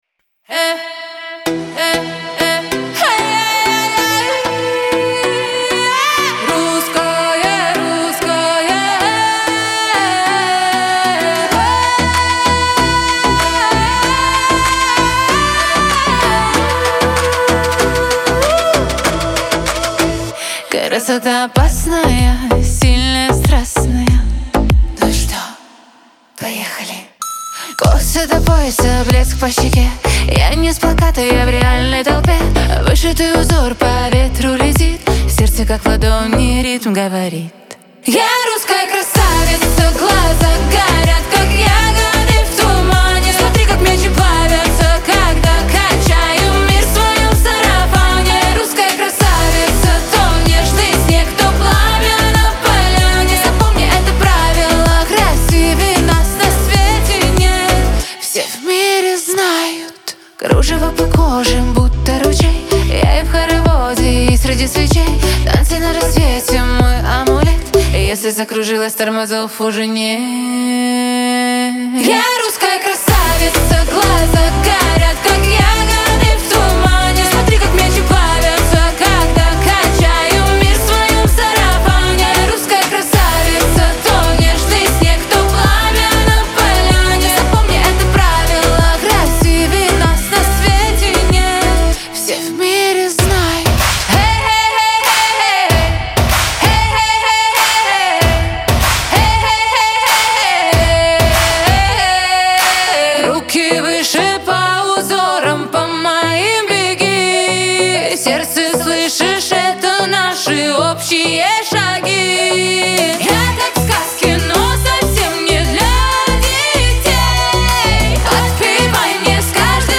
диско , эстрада
pop